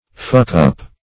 fuckup - definition of fuckup - synonyms, pronunciation, spelling from Free Dictionary